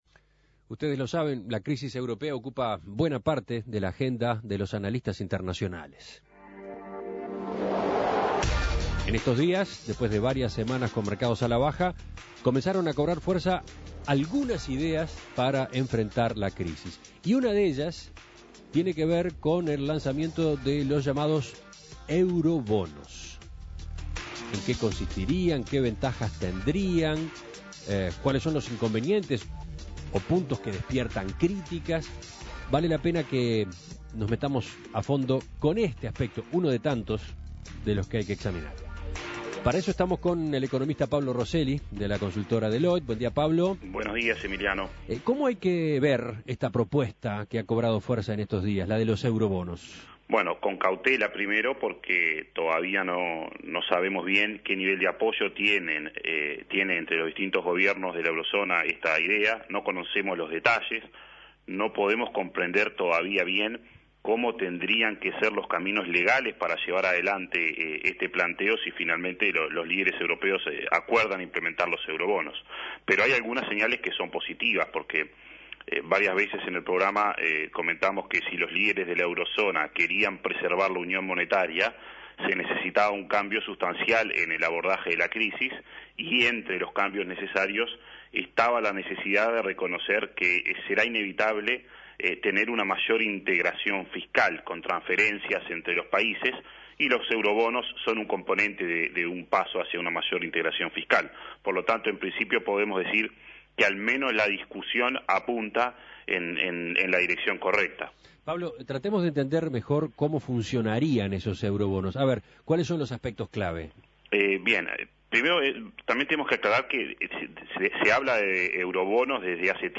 Análisis Económico Claves de la discusión sobre el lanzamiento de los eurobonos en la Eurozona